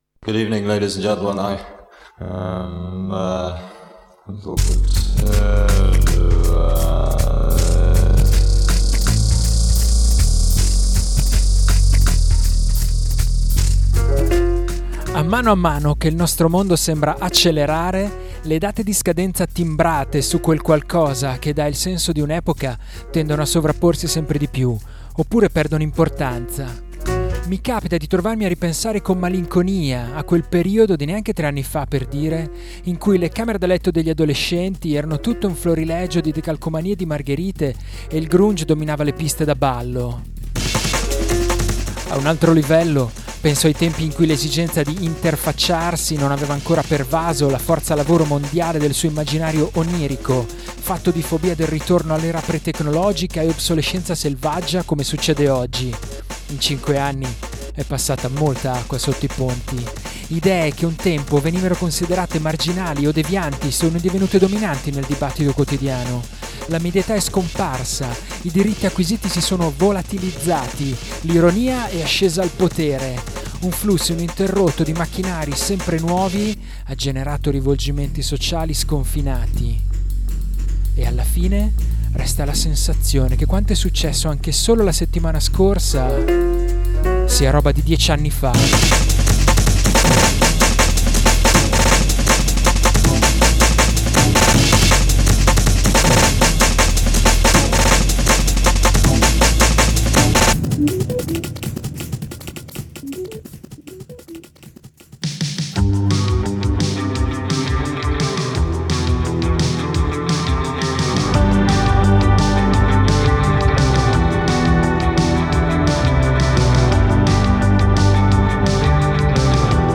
Indiepop, indie rock e brindisi!